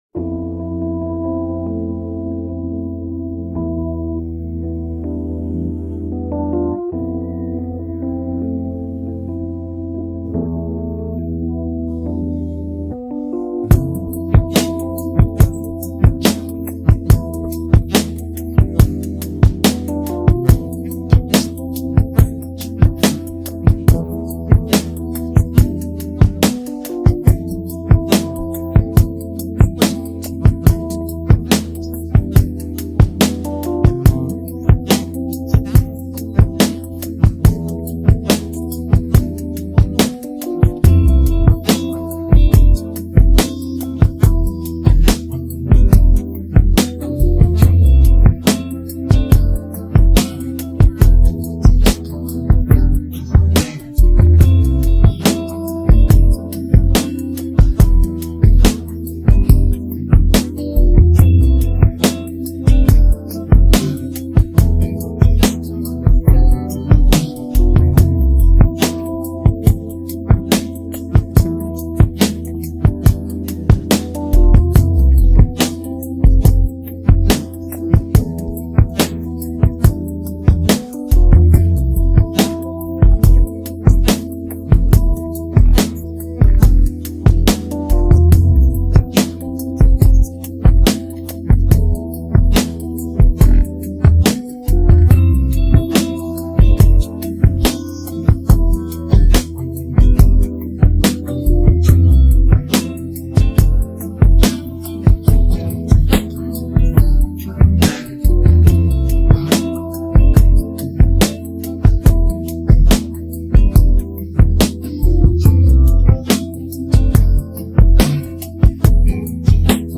BPM 141.4